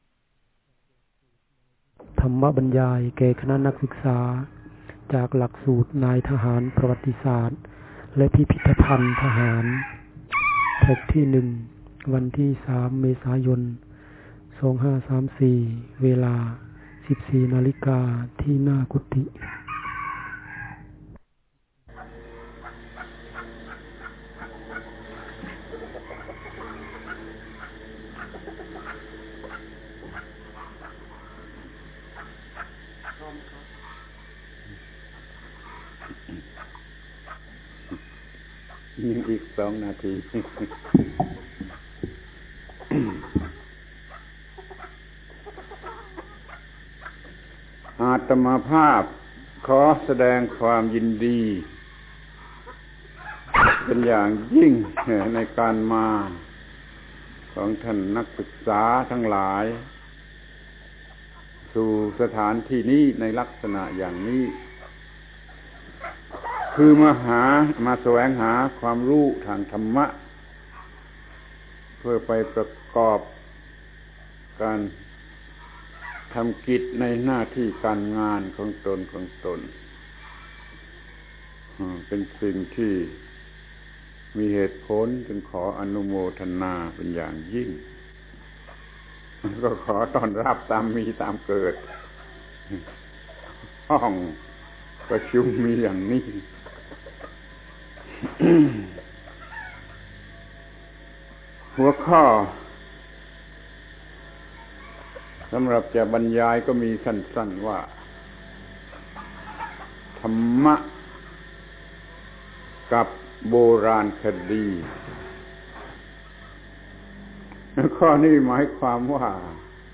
พระธรรมโกศาจารย์ (พุทธทาสภิกขุ) - ธรรมะบรรยายแก่คณะนักศึกษาธรรมะกับโบราณคดี จากหลักสูตรนายทหารประวัติศาสตร์และพิพิธภัณฑ์ทหาร ธรรมะกับโบราณคดี